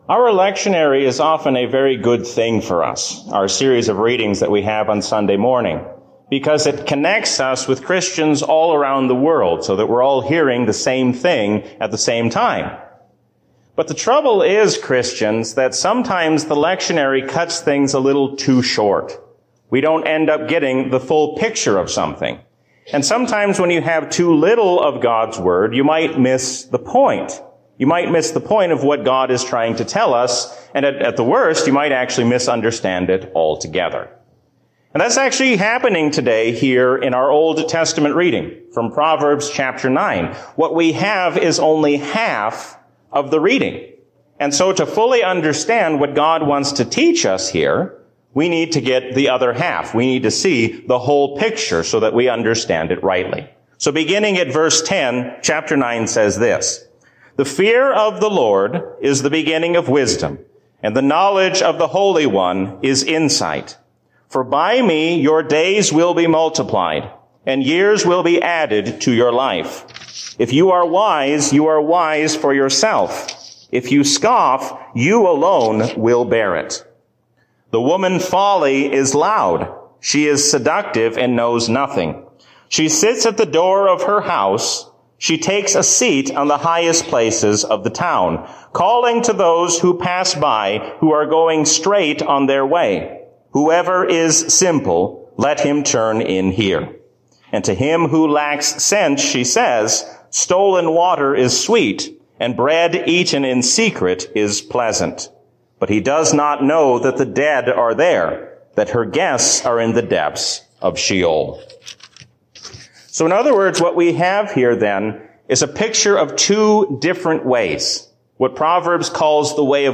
A sermon from the season "Trinity 2021." Christ's righteousness is your righteousness.